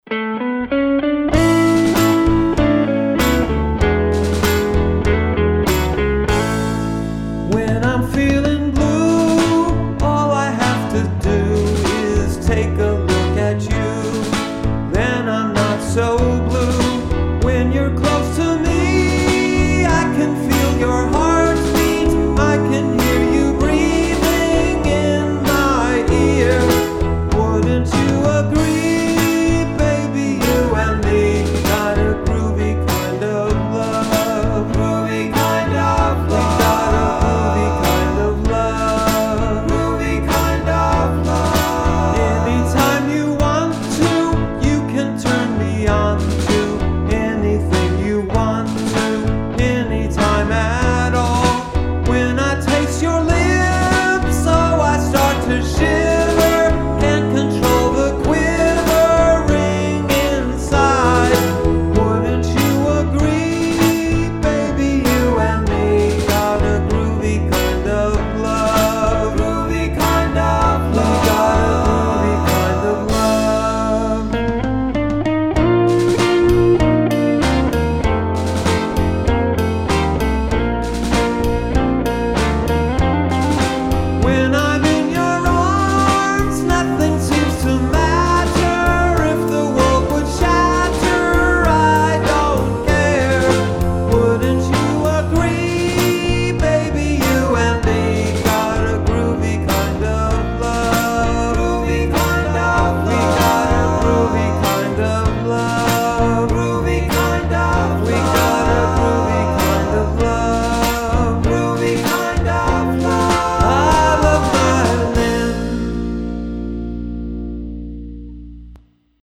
The Recording Studio